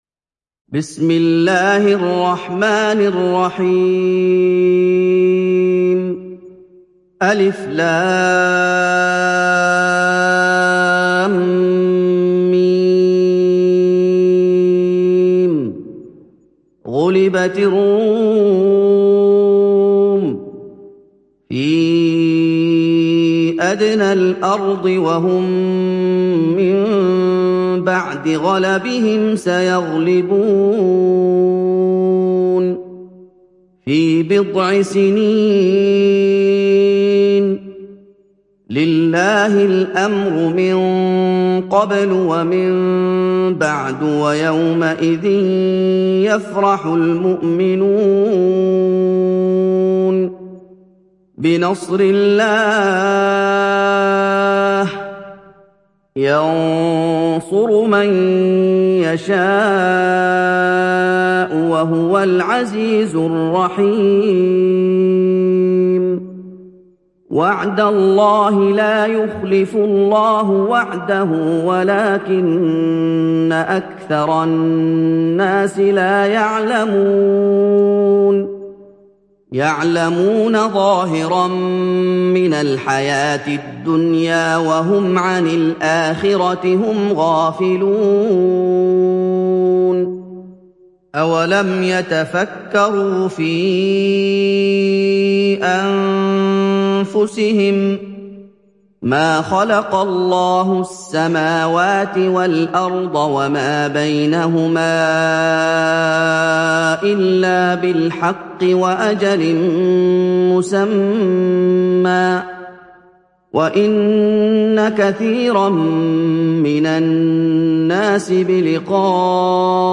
Surat Ar Rum Download mp3 Muhammad Ayoub Riwayat Hafs dari Asim, Download Quran dan mendengarkan mp3 tautan langsung penuh